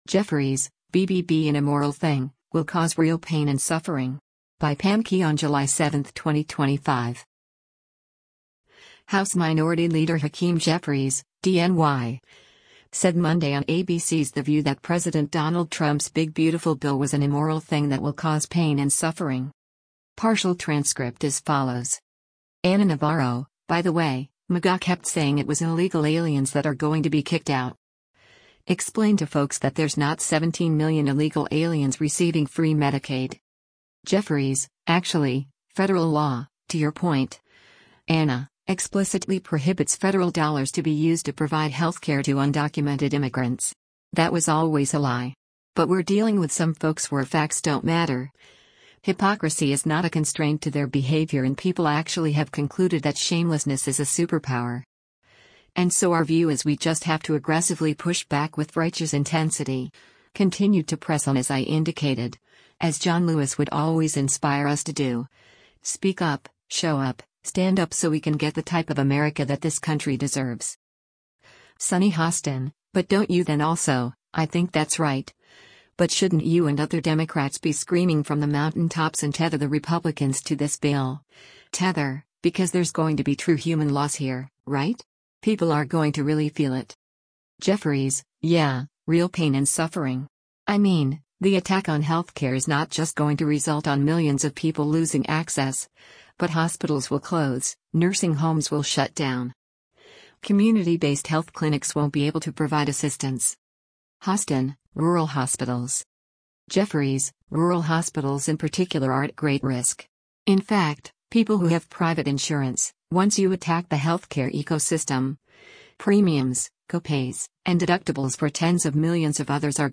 House Minority Leader Hakeem Jeffries (D-NY) said Monday on ABC’s “The View” that President Donald Trump’s “Big Beautiful Bill” was an “immoral thing” that will cause pain and suffering.